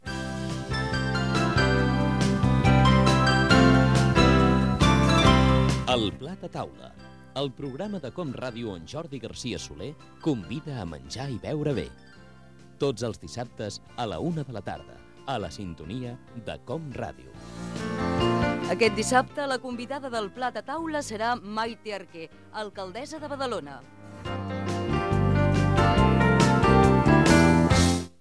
Promoció del programa en què Maite Arqué, alcaldessa de Badalona, és la convidada.